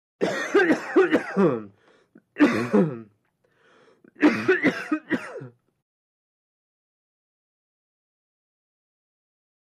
Cough | Sneak On The Lot